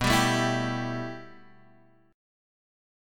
B7sus4#5 chord